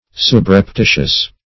Search Result for " subreptitious" : The Collaborative International Dictionary of English v.0.48: Subreptitious \Sub`rep*ti"tious\, a. [L. subreptitius.
subreptitious.mp3